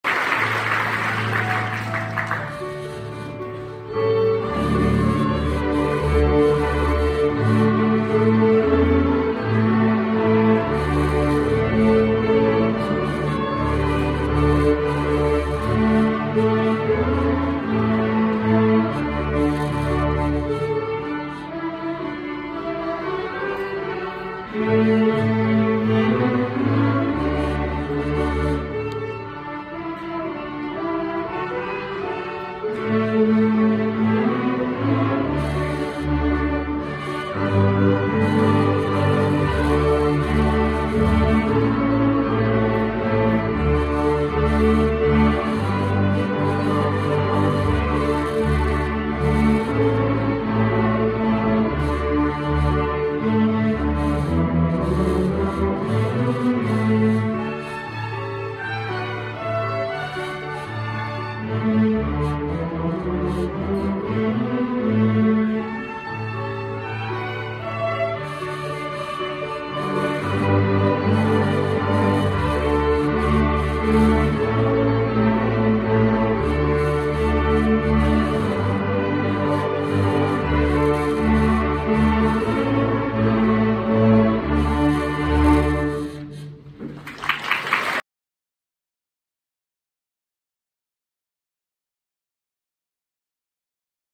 Charley Marley | Larch String Orchestra